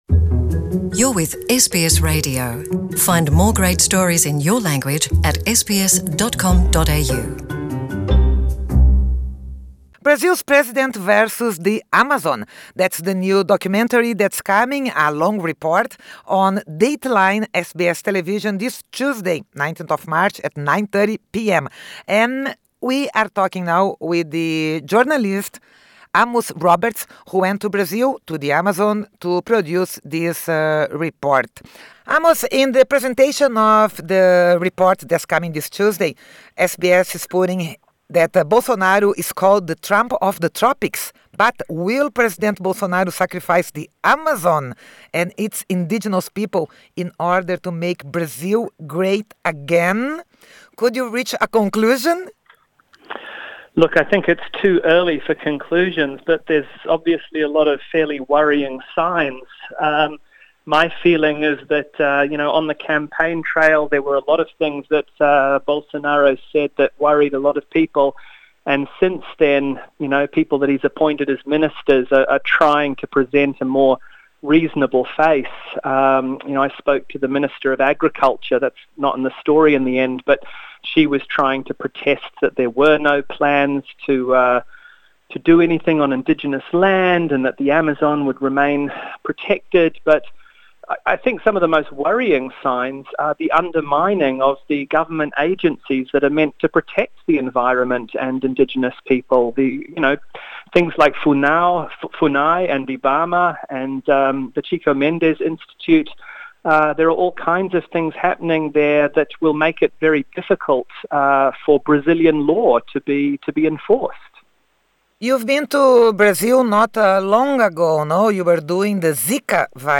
SBS Portuguese